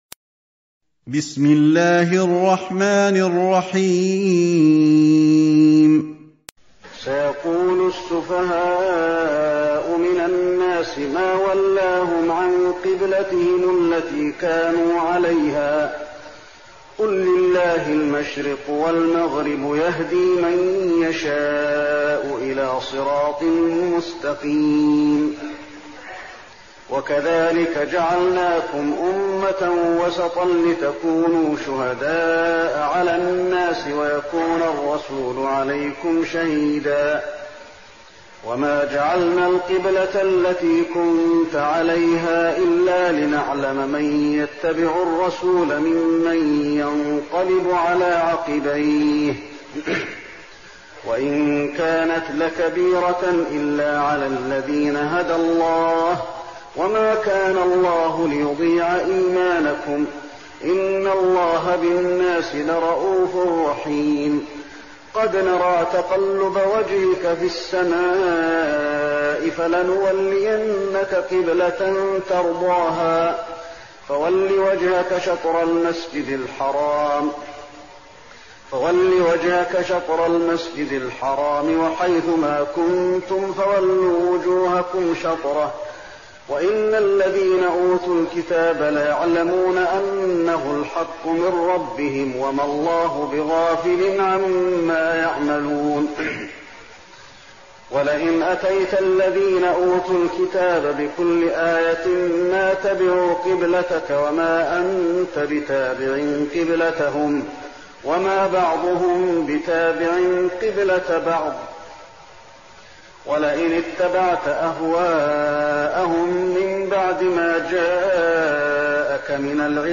تهجد رمضان 1415هـ من سورة البقرة (142-231) Tahajjud night Ramadan 1415H from Surah Al-Baqara > تراويح الحرم النبوي عام 1415 🕌 > التراويح - تلاوات الحرمين